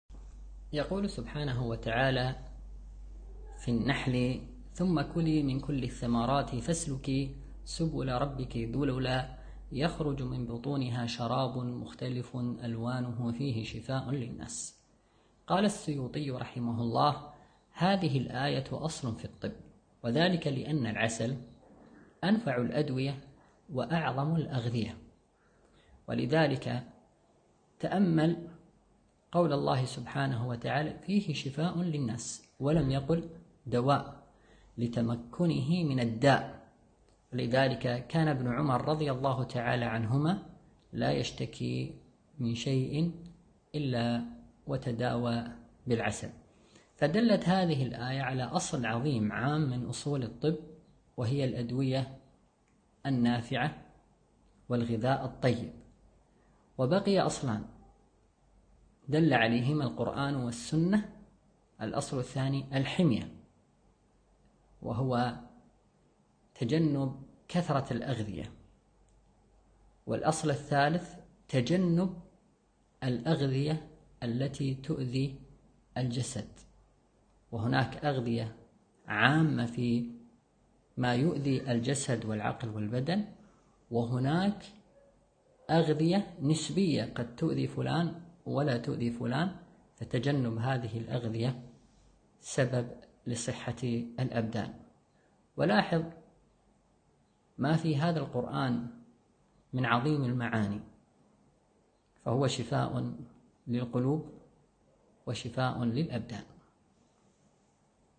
التنسيق: MP3 Mono 22kHz 64Kbps (CBR)